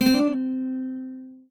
guitar_c1d1c1.ogg